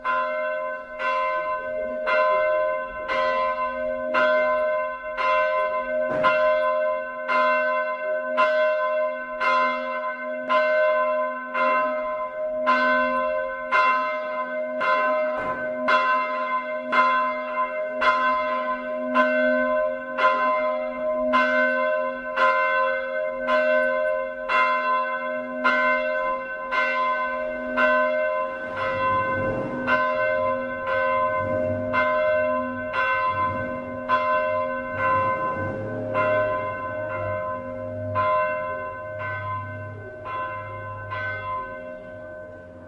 描述：教堂的响铃，城市噪音和鸟在背景中。录制在Saluzzo市中心（意大利Piamonte），使用带内置麦克风的PCMM10录音机
Tag: 教堂 敲打 氛围 现场录音 城市